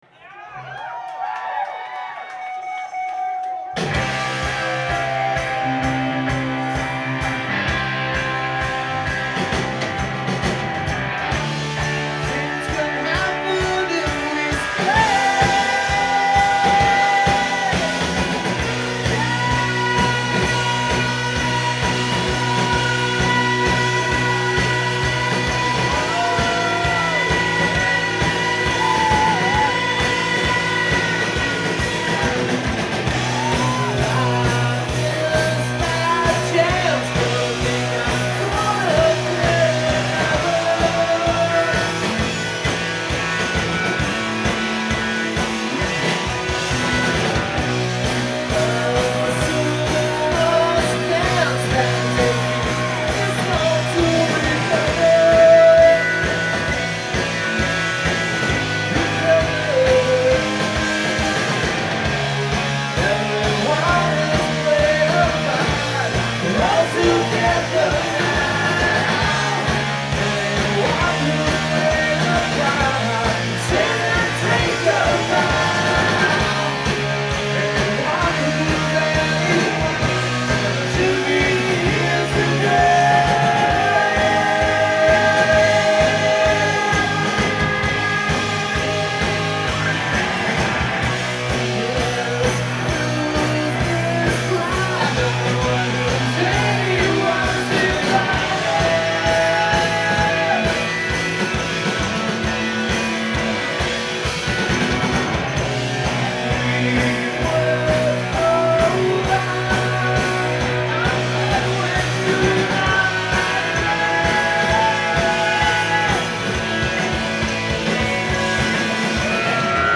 Live at The Middle East Downstairs
in Cambridge, Massachusetts
encore…